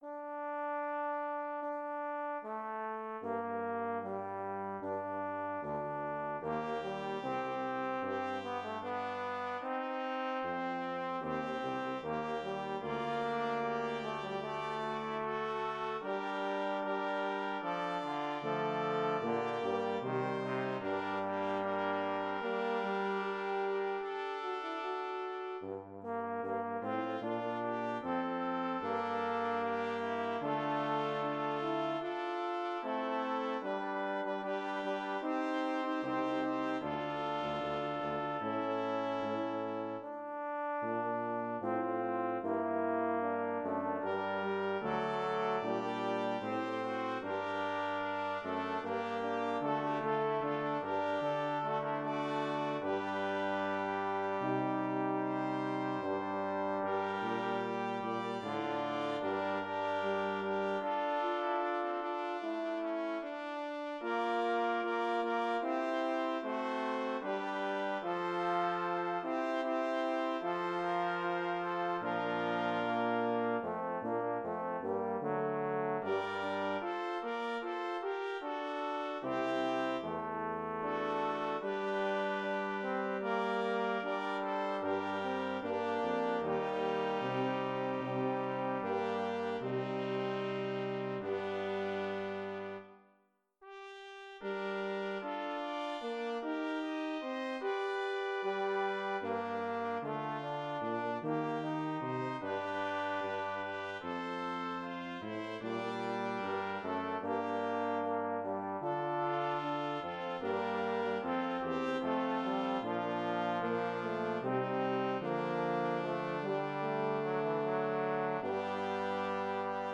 Synthesized Performance *#624065
Performers MIDI Copyright Creative Commons Attribution-ShareAlike 4.0 [ tag / del ] Misc.